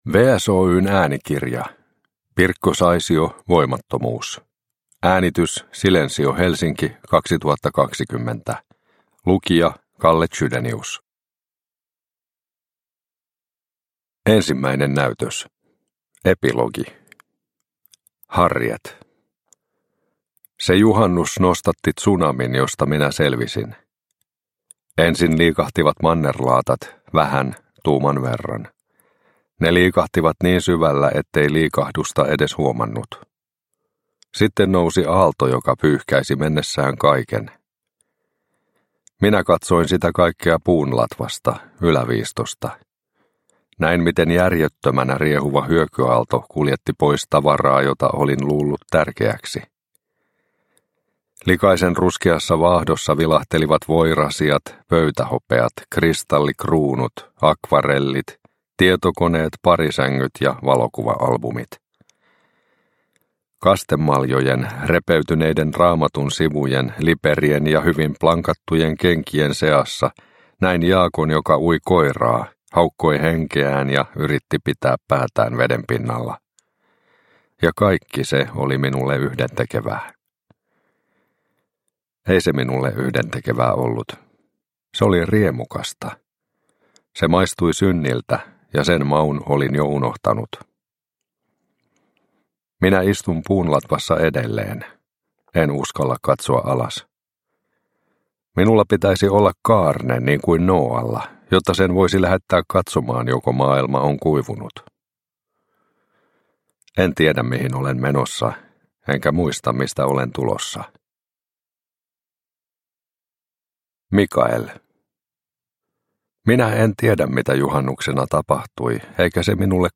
Voimattomuus – Ljudbok